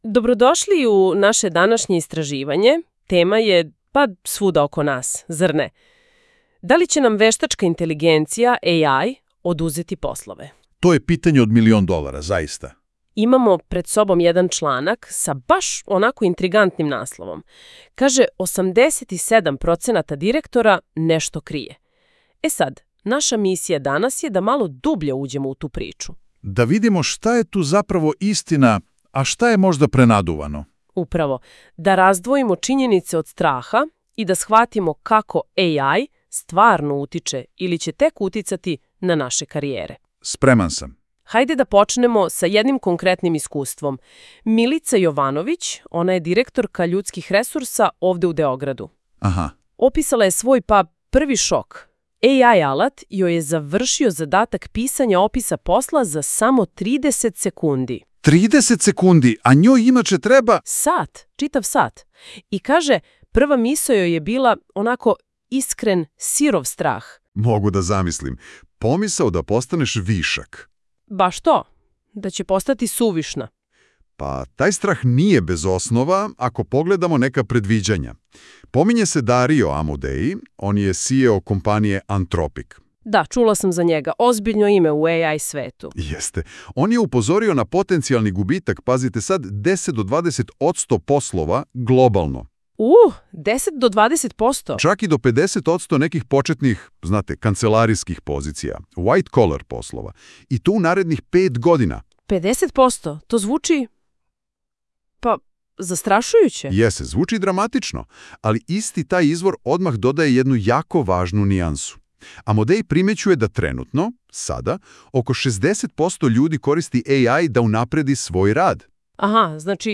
Google NotebookLM je kreirao fascinantan podcast razgovor upravo na osnovu sadržaja koji sledi. Dva AI voditelja diskutuju o transformaciji rada i AI budućnosti na način koji će vas oduševiti – njihova hemija i način objašnjavanja čine ovu kompleksnu temu pristupačnom i zabavnom.